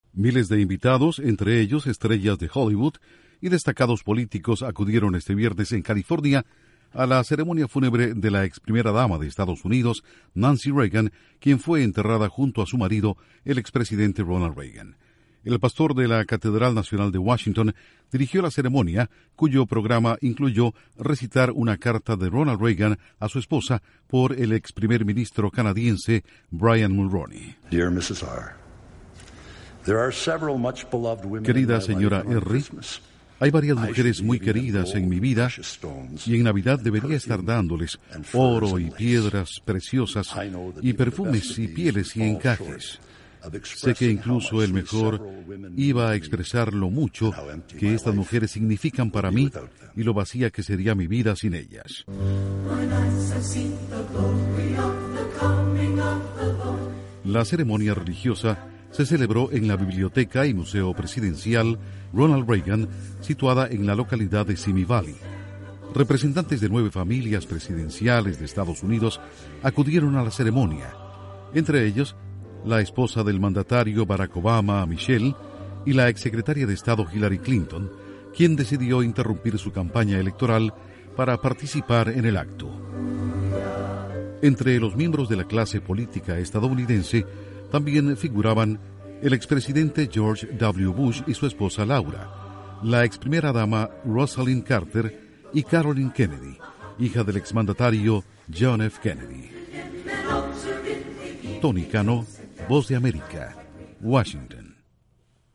Miles de personas, entre estrellas de Hollywood y del mundo de la política acuden al funeral de Nancy Reagan. Informa desde la Voz de América